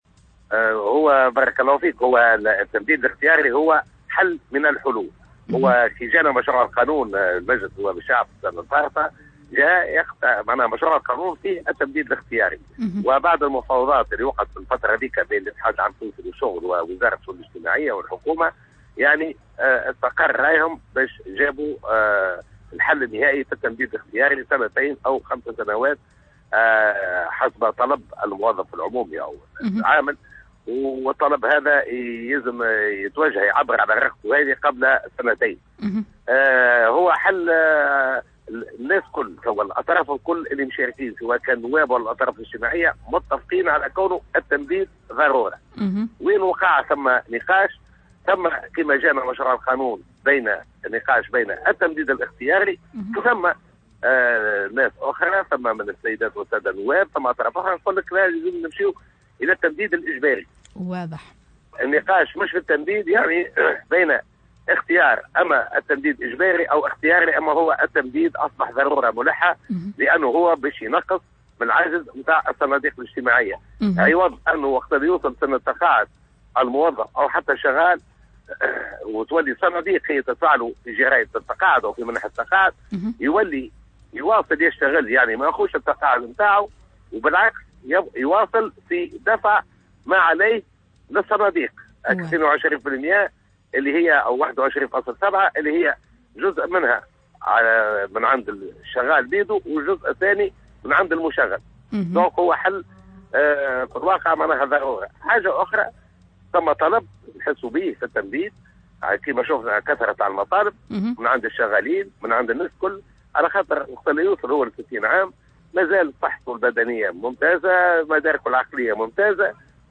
قال رئيس لجنة تنظيم الادارة و القوات الحاملة للسلاح جلال غديرة في مداخلته اليوم في برنامج “ساعة حرة” ان مشروع القانون الذي عرض في مجلس النواب تضمن التمديد في سن التقاعد كحل من الحلول للحد من عجز الصناديق الاجتماعية